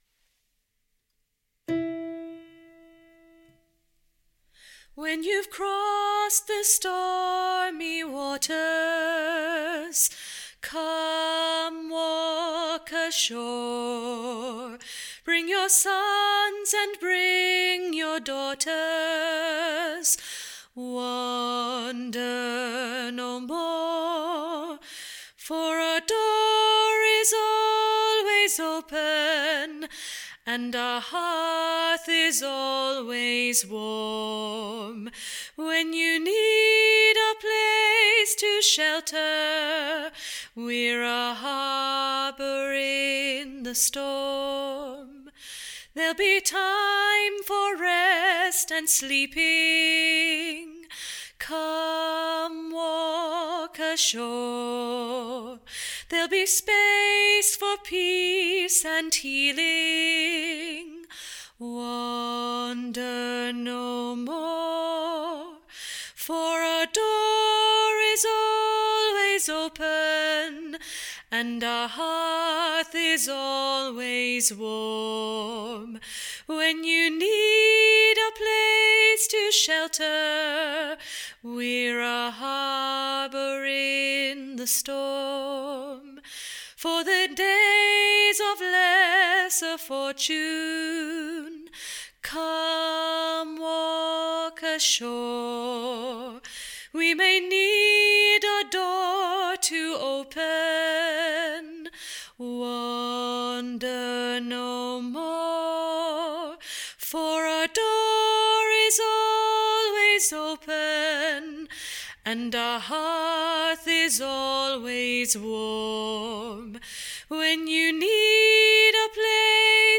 Harbour Alto